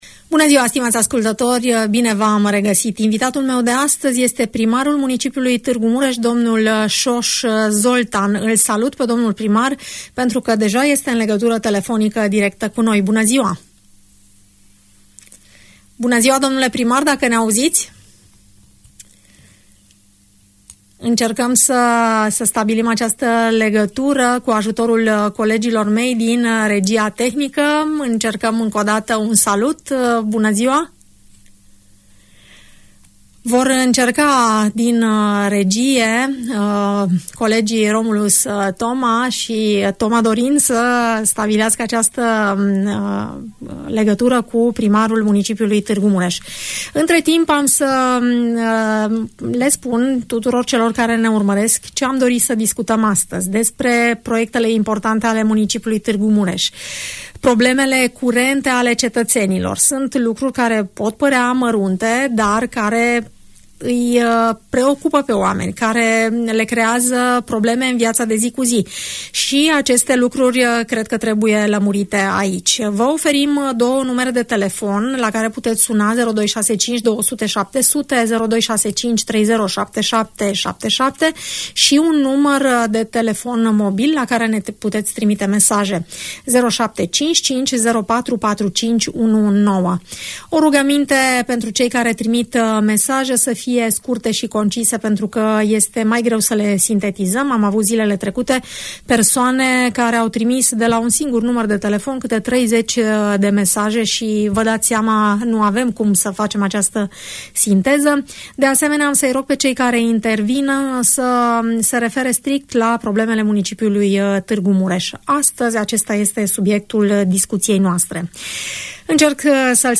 Audiență radio cu primarul Soos Zoltán - Radio Romania Targu Mures